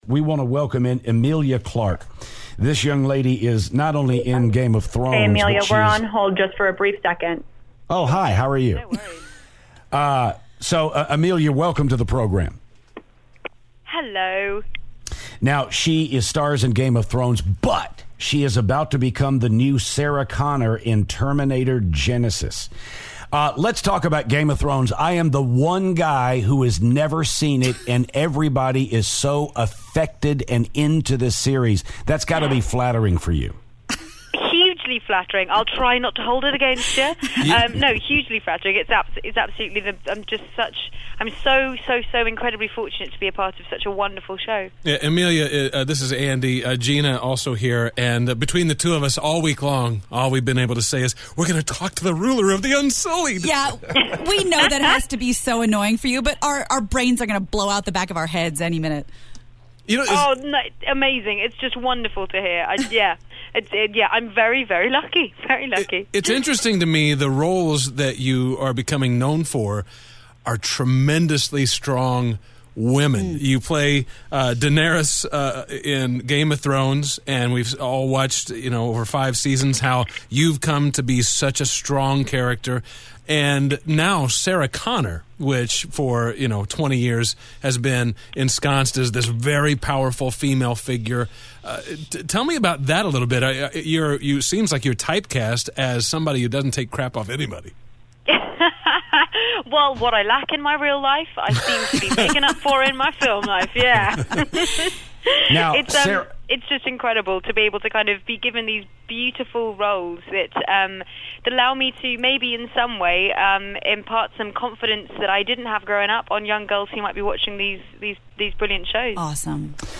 "Game Of Thrones" and "Terminator: Genisys" star Emilia Clarke calls the show and does a pretty good valley girl impression.